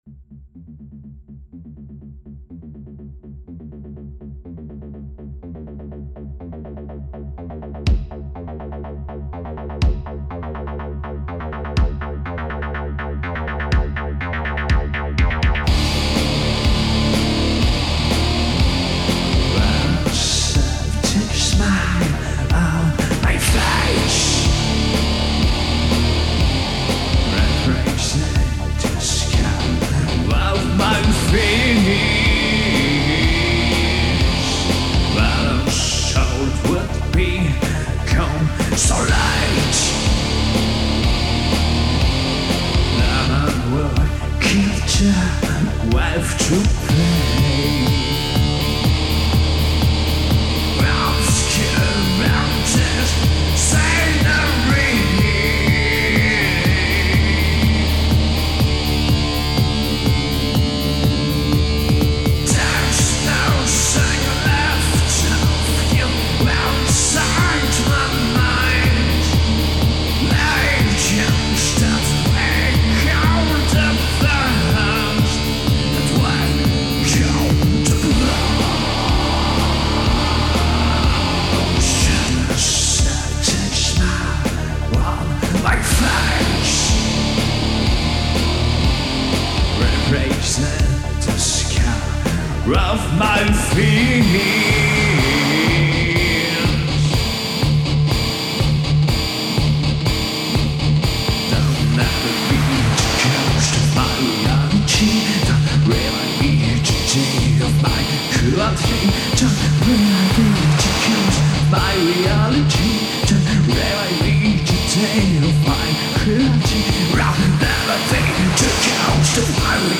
This Electro-Metal band from Namur
Chant
Basse - Programmation - Sampling
Guitare